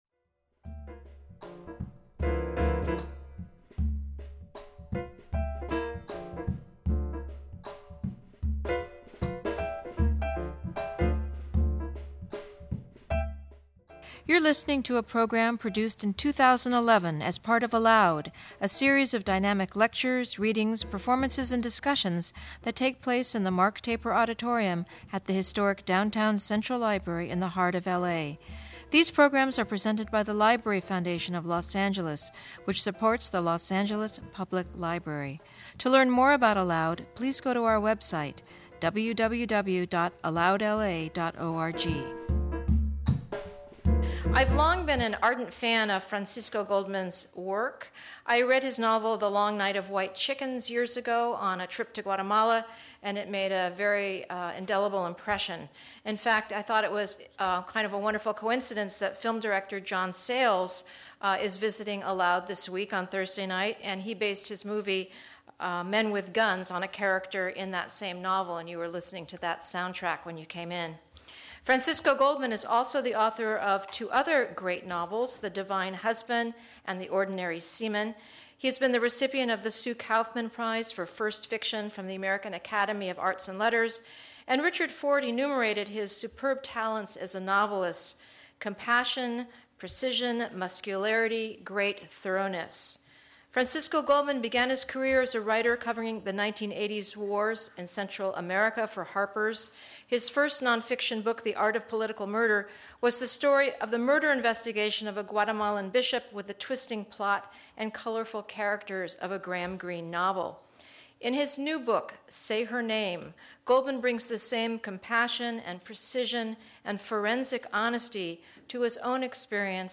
In conversation with Rachel Kushner